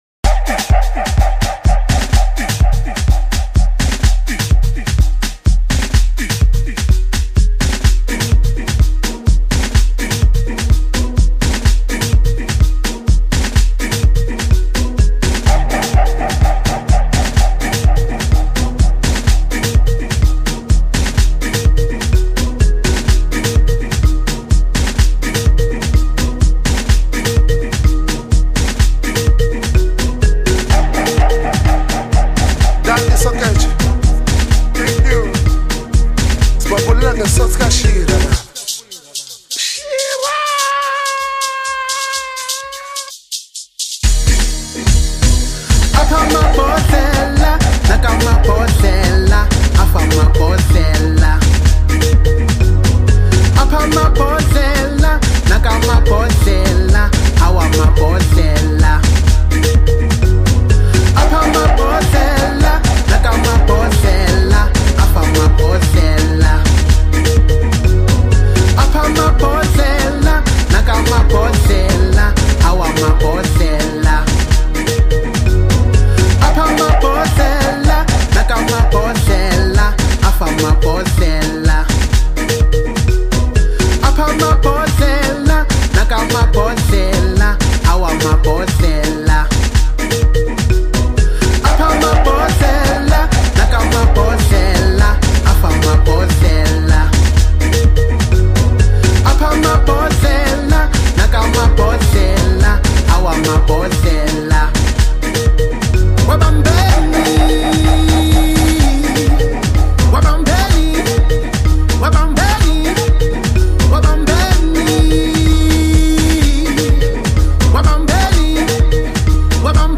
House singer
Gqom inspired tune